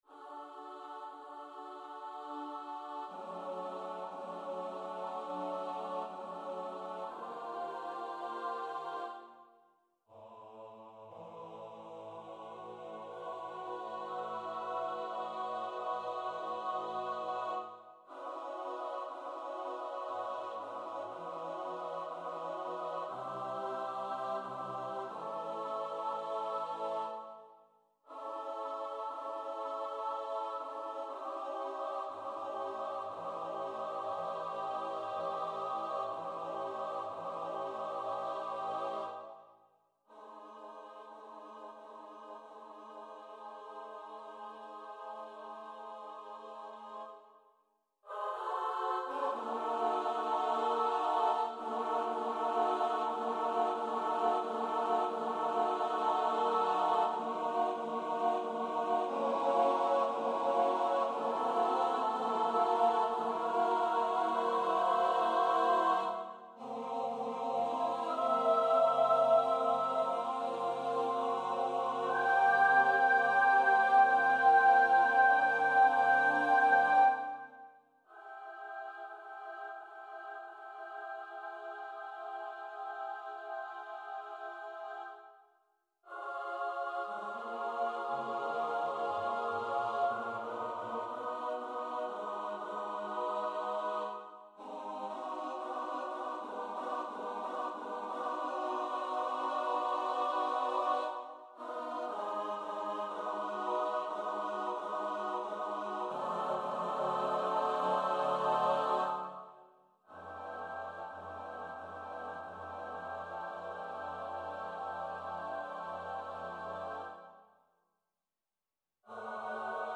for mixed voice choir
This a capella anthem